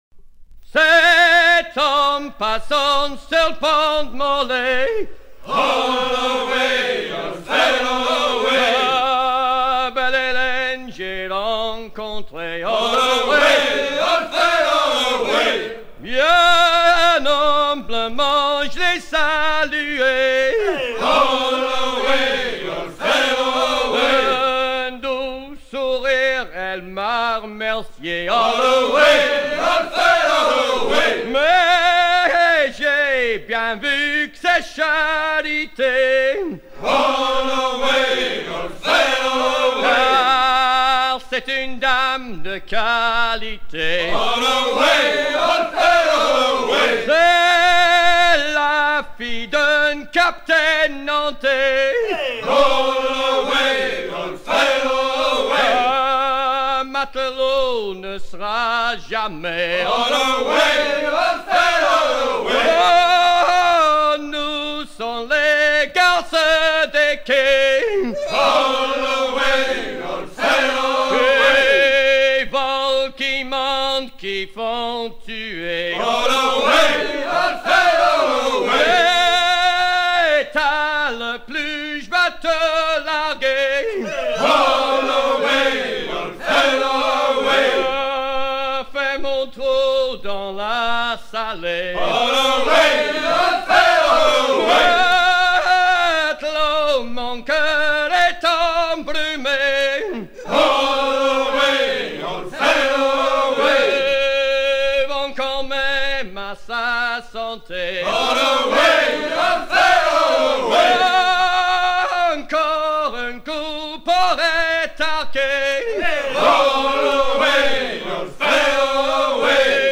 gestuel : à hisser à grands coups
circonstance : maritimes ; gestuel : travail
Pièce musicale éditée